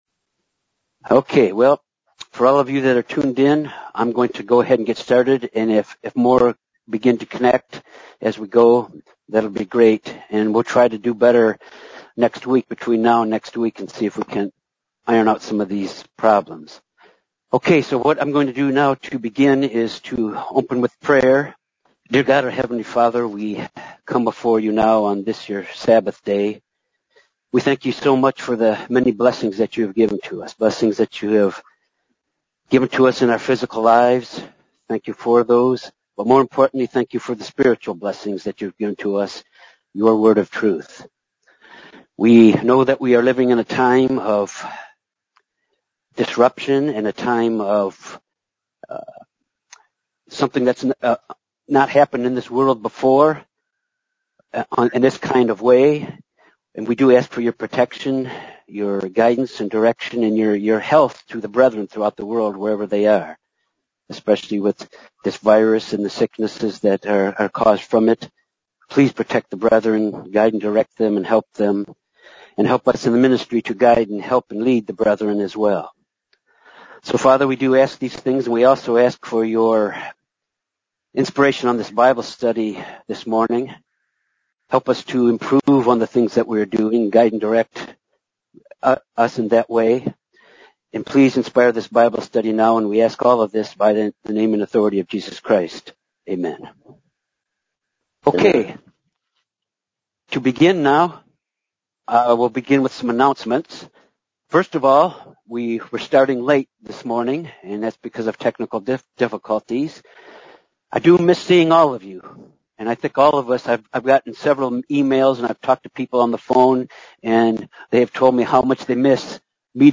This Bible study concludes the Book of Numbers and examines establishing cities for the Levites within the inheritance of each tribe of the Israelites, six which were to be cities of refuge where those who killed someone either intentionally or unintentionally could flee and be protected from blood revenge until they could be judged by the congregation of the people. In the final book of Numbers the instructions from God through Moses concerning inheritance by the daughters of a father without a male heir was established.